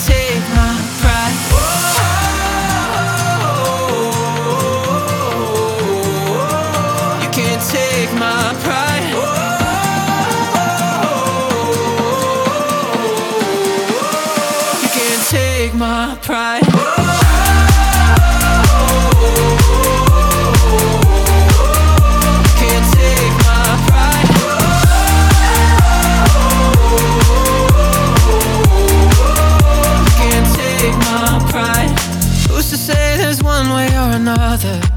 2025-05-16 Жанр: Танцевальные Длительность